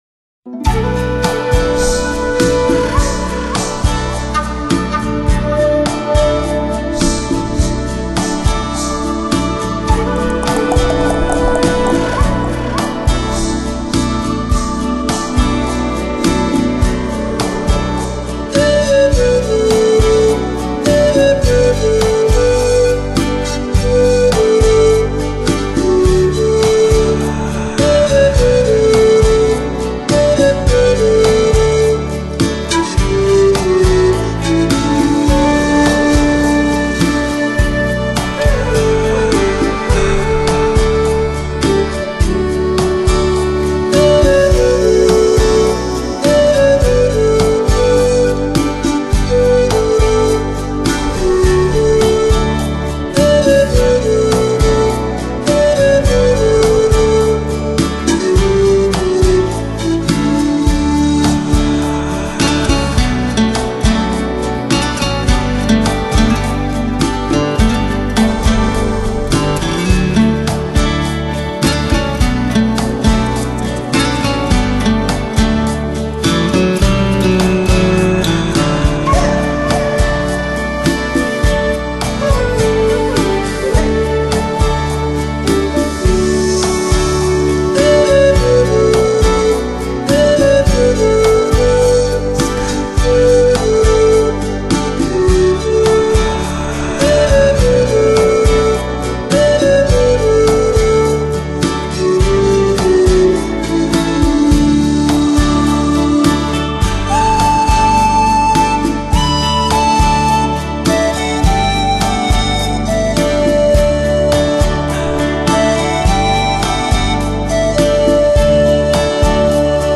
Genre: New Age, Folk, Native American, Panflute